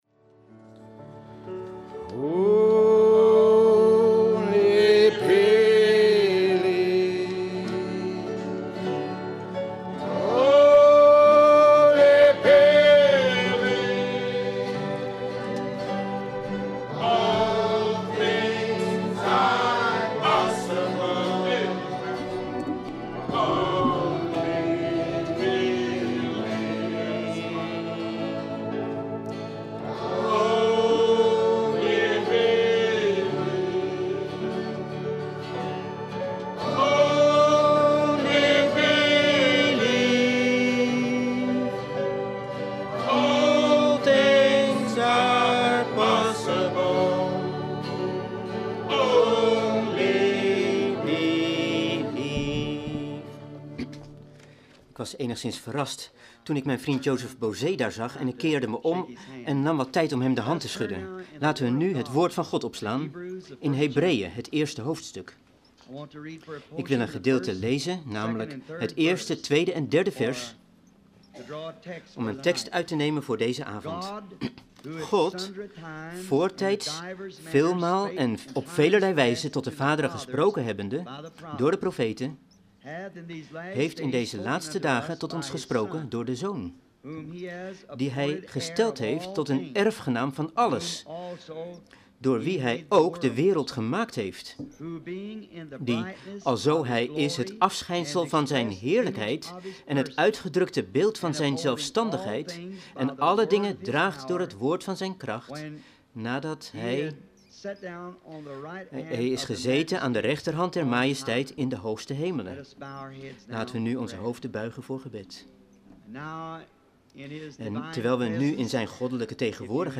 Prediking
Locatie Denham Springs high school Denham Springs , LA